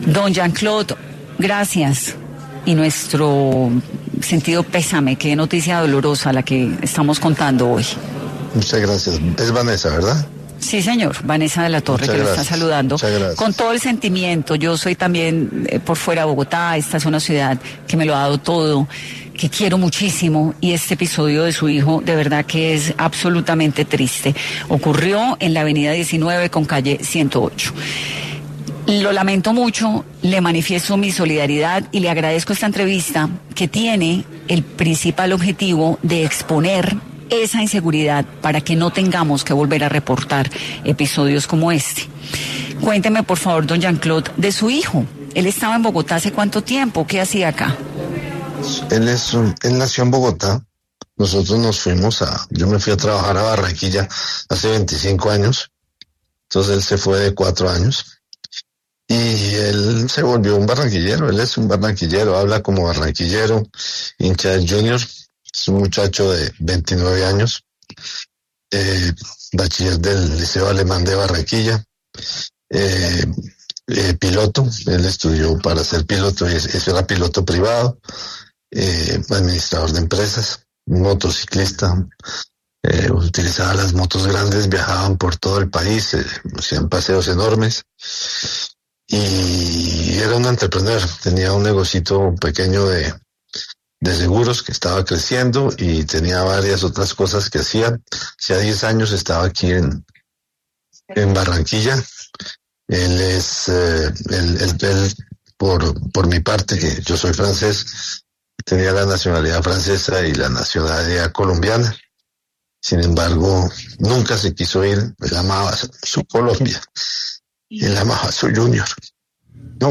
En entrevista con 10AM